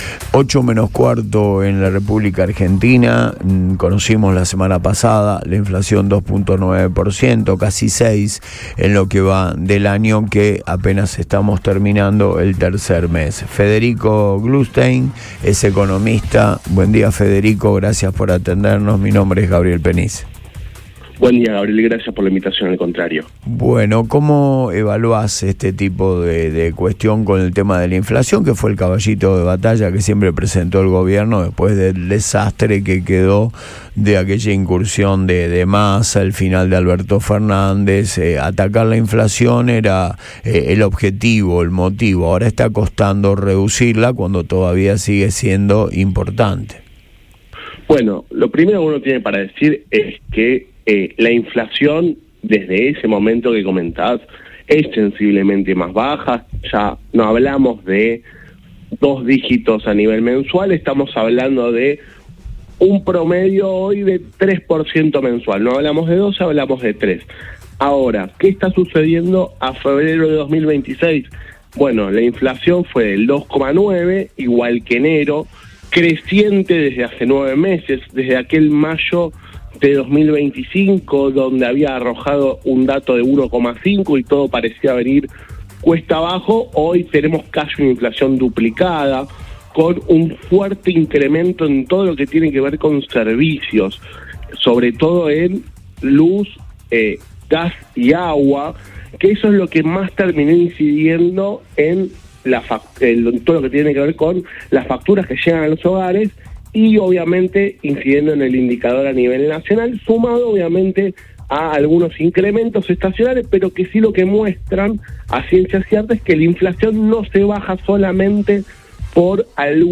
pasó por los micrófonos de Radio Boing